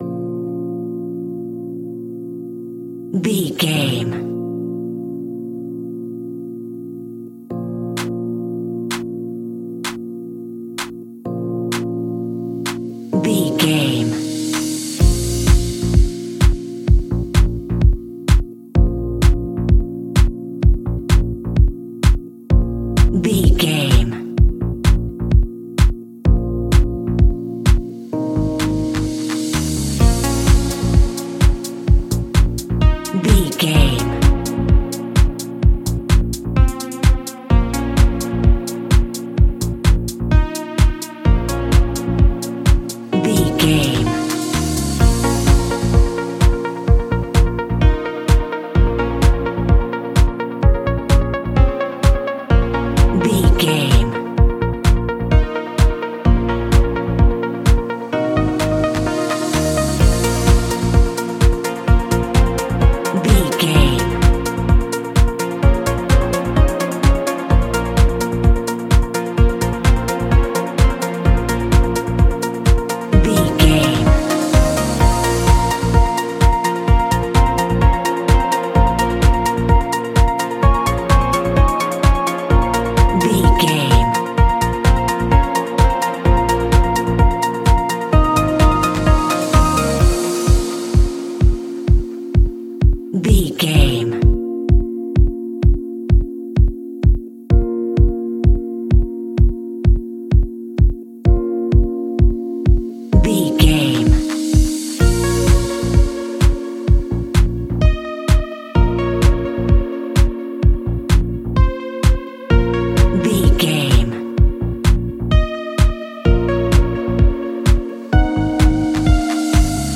Aeolian/Minor
B♭
groovy
uplifting
driving
energetic
drum machine
synthesiser
bass guitar
funky house
disco
upbeat
instrumentals
wah clavinet